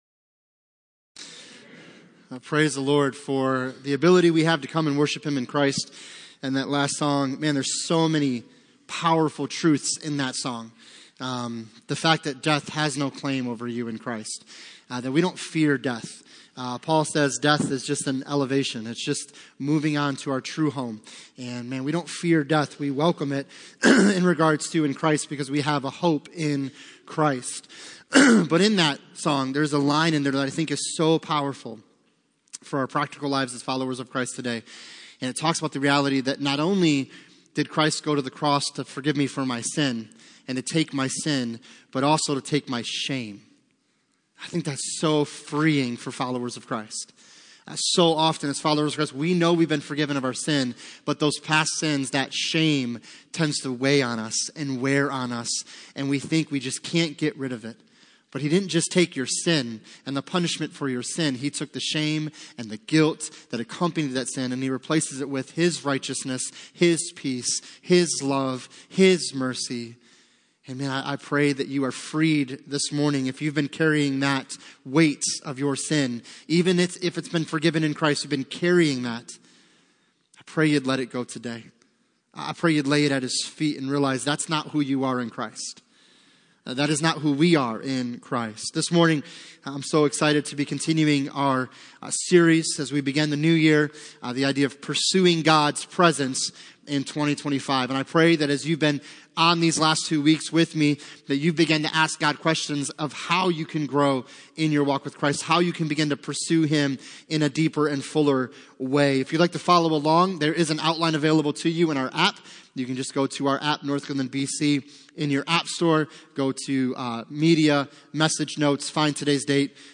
Passage: Romans 8:1-39 Service Type: Sunday Morning